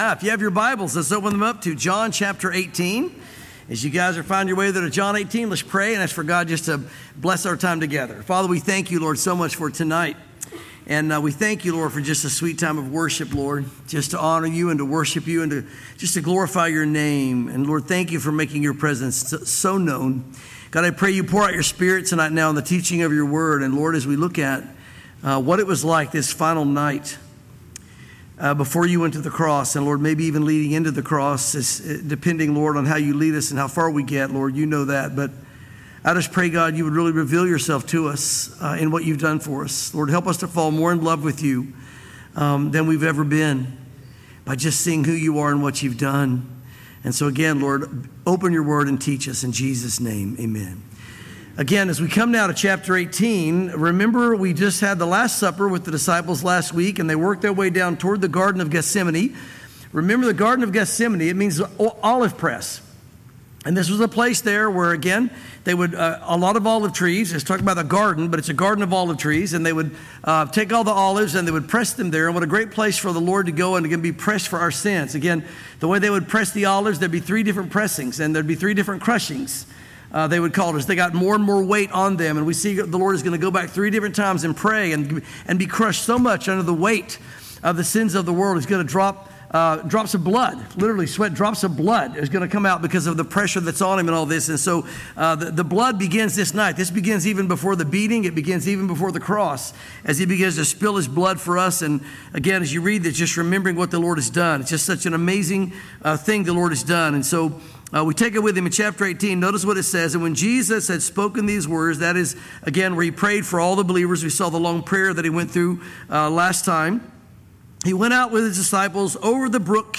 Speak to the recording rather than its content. Calvary Chapel Knoxville